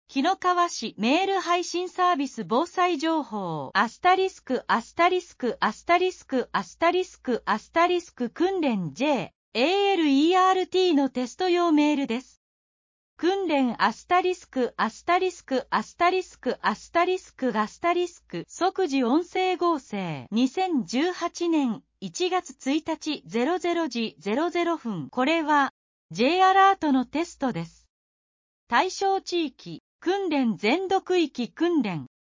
即時音声書換情報
【訓練】***** 「即時音声合成」 2018年01月01日00時00分 これは、Ｊアラートのテストです。